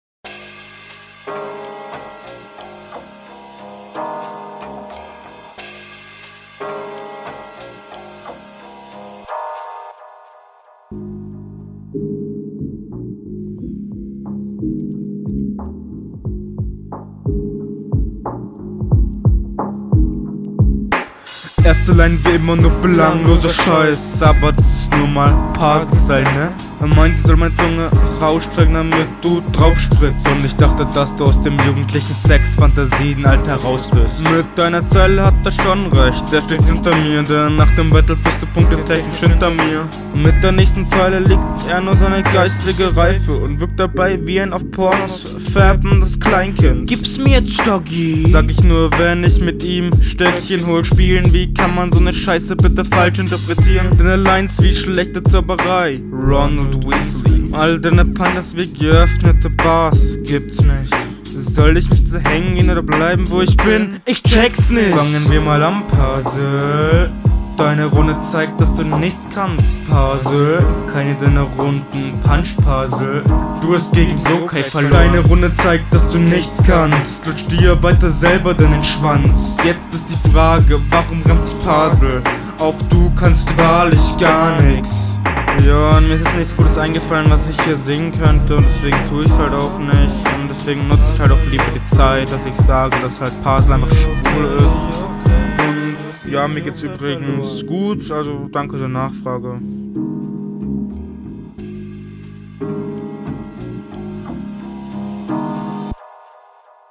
Mische gefällt mir besser als in den vorherigen Runden. Doubles sind allerdings wieder off.